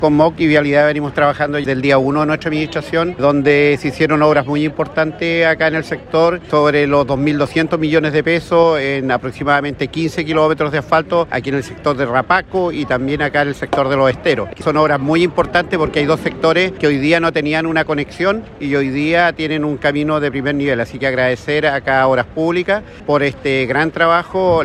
La ceremonia se desarrolló este miércoles con la presencia de autoridades regionales y locales.
Alcalde-Andres-Reinoso-1-1.mp3